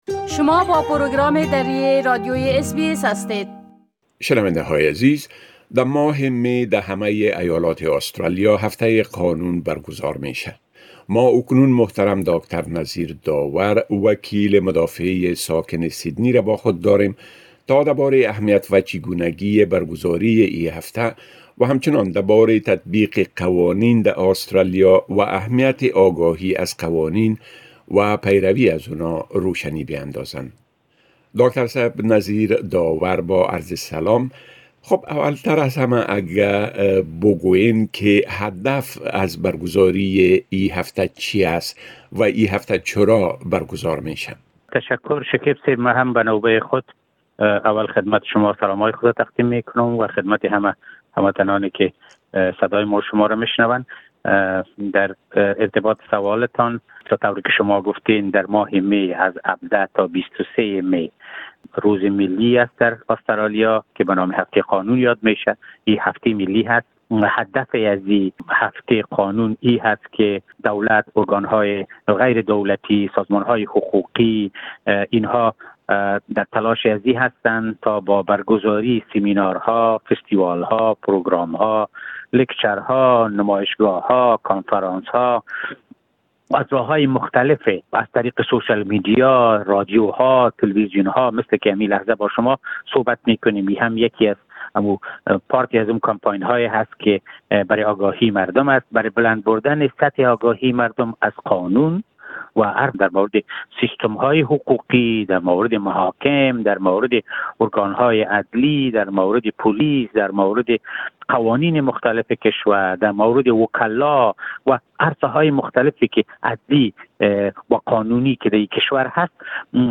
مصاحبه انجام داده است که شما میتوانید معلومات بیشتر را در مصاحبه بشنوید.